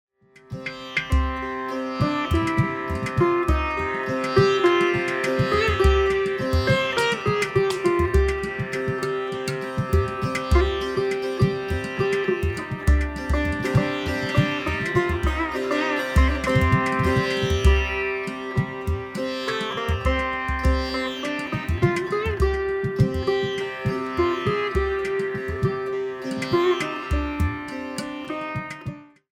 Sitar, Handsonic Tabla